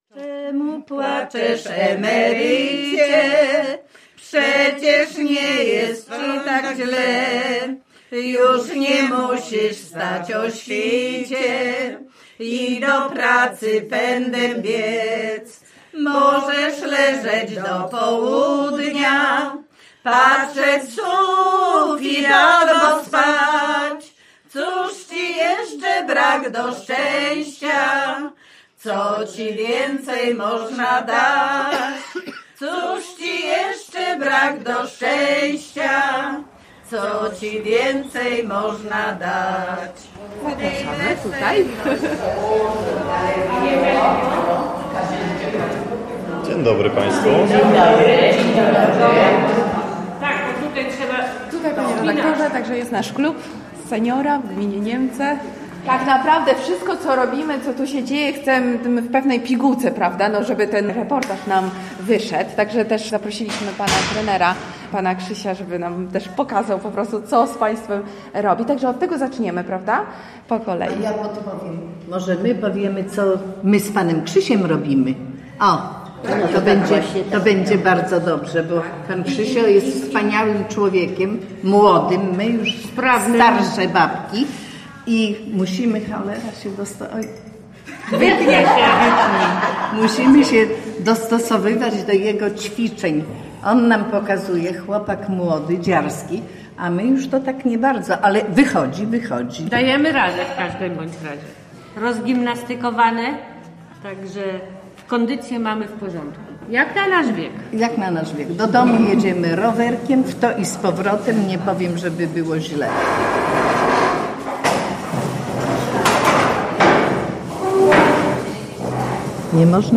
KALEJDOSKOP REGIONALNY Reportaż